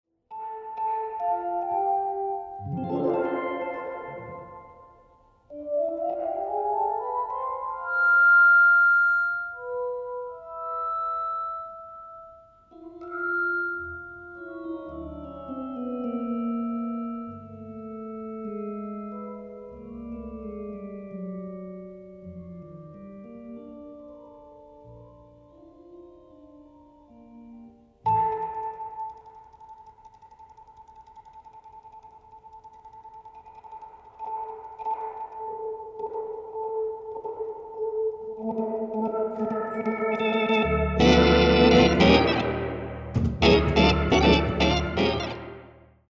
No Leslie speakers were used!
jazz pianist/organist